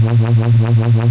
So here’s the Waft Wave sine wave Evolve PWM Scan over the whole 64 slot range:
This produces a clean, monotonic PWM sweep.
I’m at work today but here’s the syx and ping ponged audio of banks to compare if you can upload to MnM: